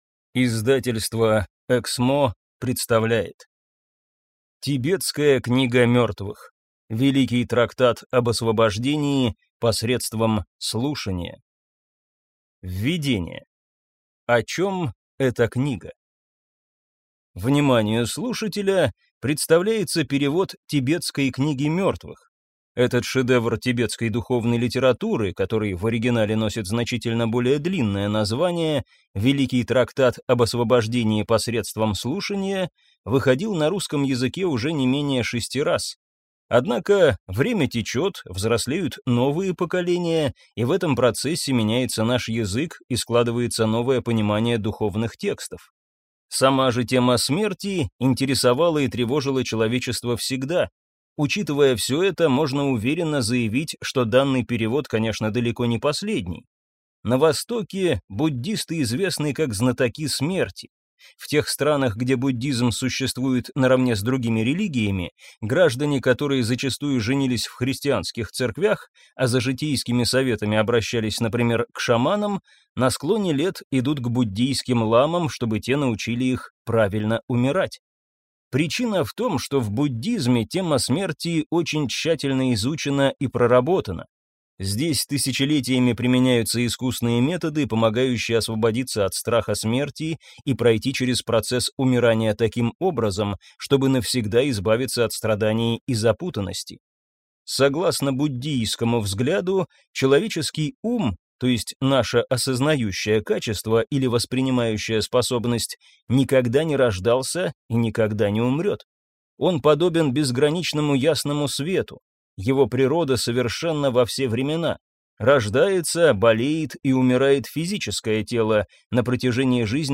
Аудиокнига Тибетская книга мертвых. Великий трактат об освобождении посредством слушания | Библиотека аудиокниг